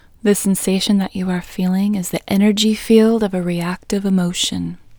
OUT Technique Female English 2